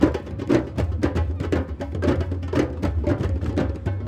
drum_circle.wav